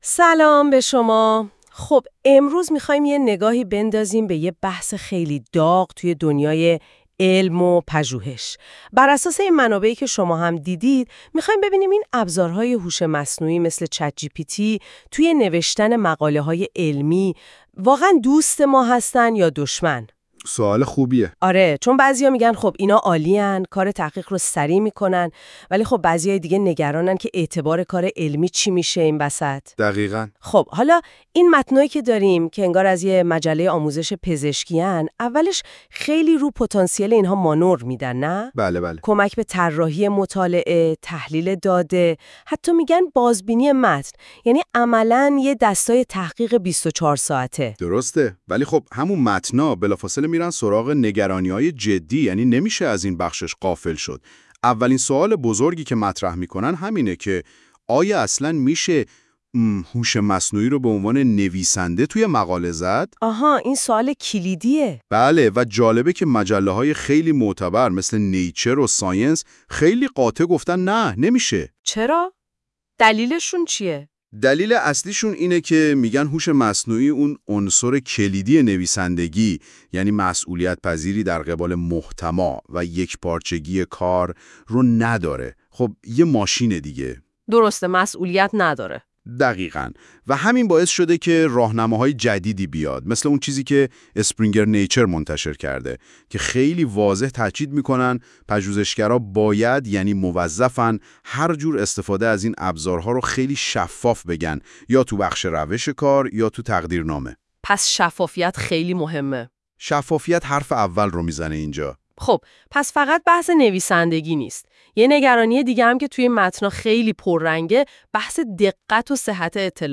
این ابزار می‌تواند محتوای سند شما را به یک گفت‌وگوی صوتی بین دو میزبان هوش مصنوعی تبدیل کند که نکات کلیدی را به شکلی جذاب توضیح می‌دهند.
نتیجه این کار برای مقاله‌ای که من به این ابزار دادم بسیار فوق العاده بود؛ با زبان فارسی روان و سلیس دو نفر به صورت گفتگو محور جزئیات این مقاله عملی را بیان می‌کردند.
البته گاهی اوقات امکان دارد صداها به موضوعات فرعی اشاره کنند یا مکث‌هایی برای تبلیغات فرضی داشته باشند؛ اما این جزئیات کوچک در برابر ارزش کلی این قابلیت ناچیز هستند.